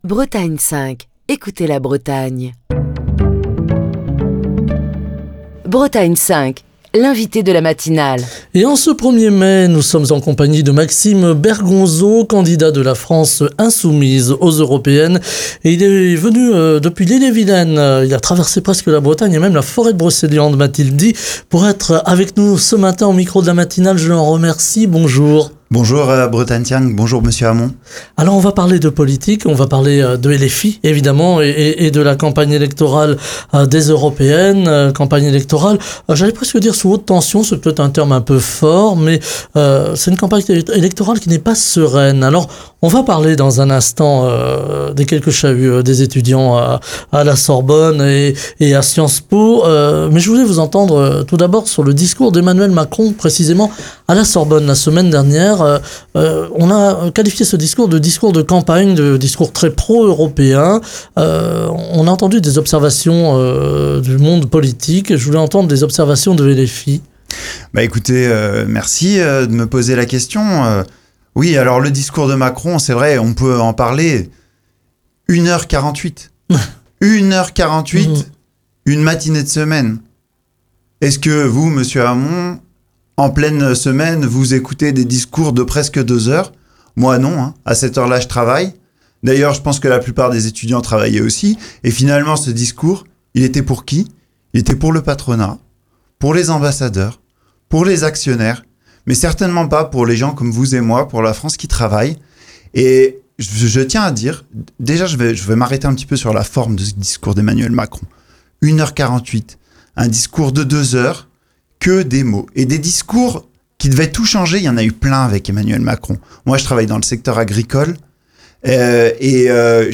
est l'invité de la matinale de Bretagne 5, dans le cadre de la campagne pour les élections européennes du 9 juin. Il aborde les grandes thématiques portées par La France Insoumise, comme la justice sociale, l'environnement, l'économie avec la taxation des profits, l'Europe sociale, les conséquences de la guerre en Ukraine et l'élargissement de l'Union européenne avec l'entée de nouveaux pays.